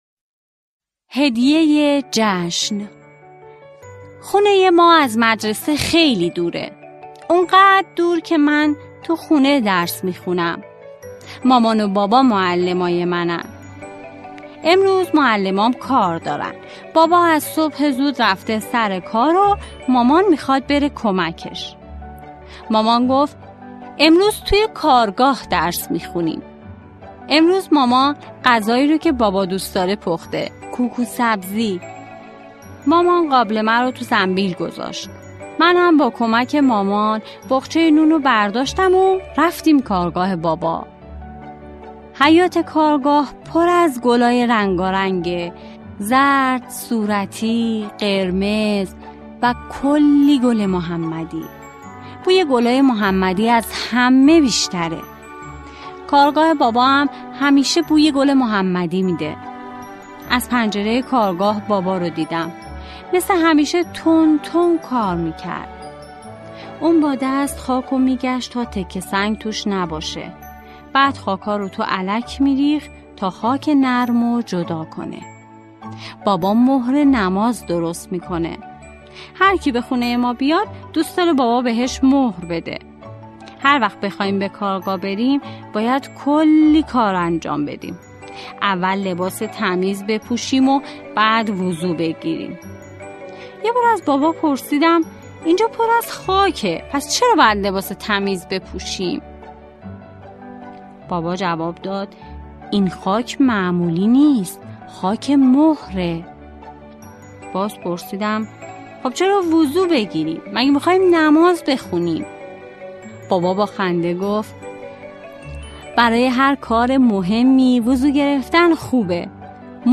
قصه های کودکانه؛ هدیه جشن ؛ مهر کربلا